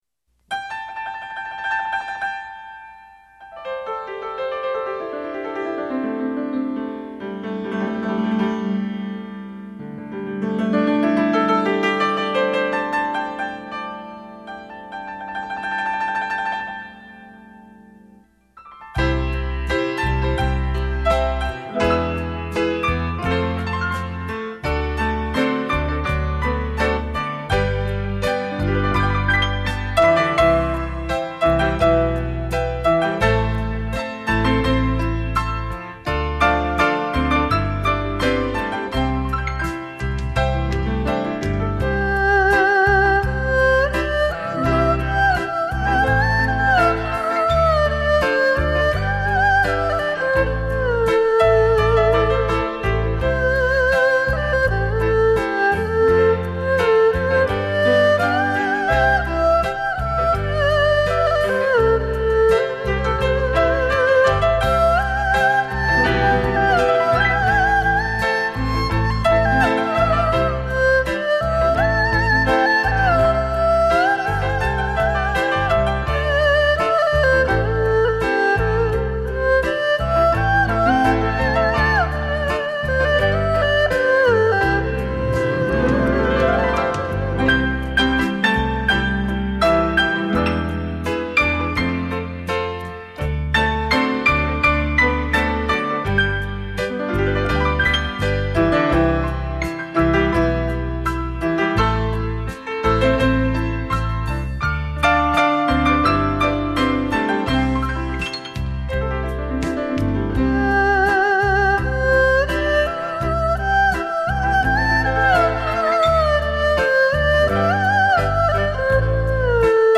二胡演奏
徐徐丝竹声 依如走入幽幽小径